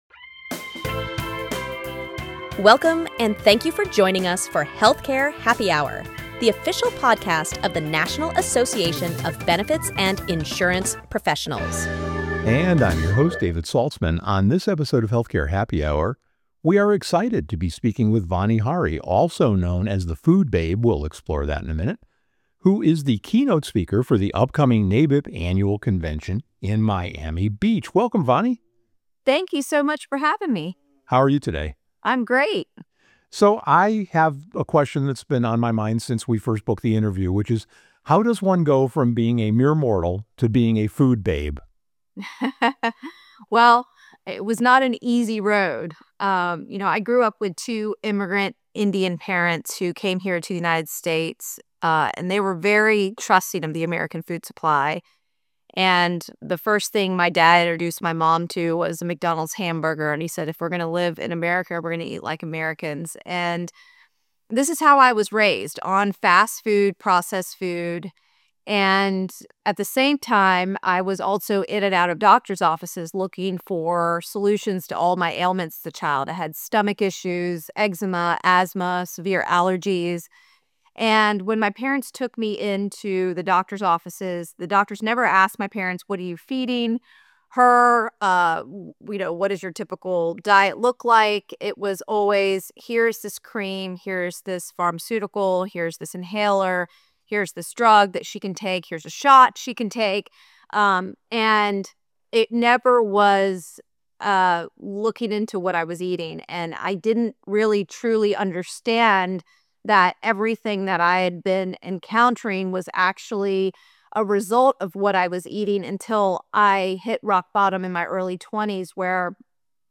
interviews Vani Hari